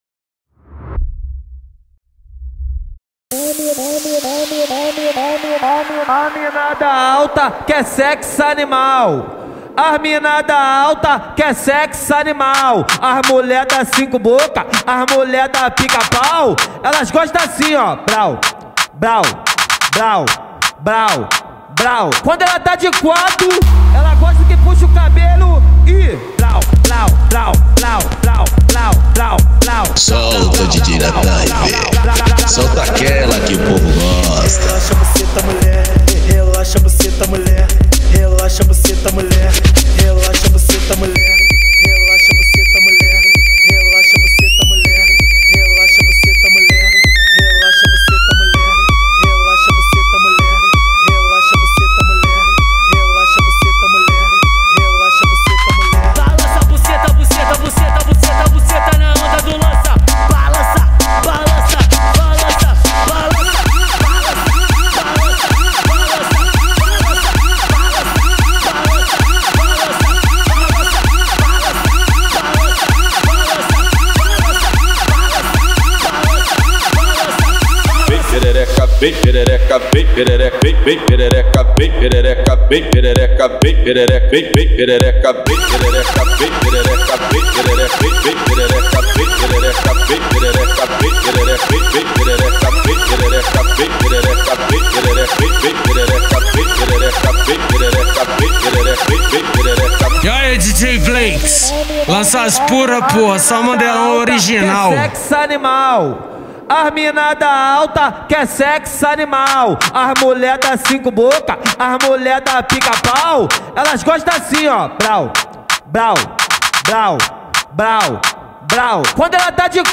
2024-12-23 00:46:57 Gênero: MPB Views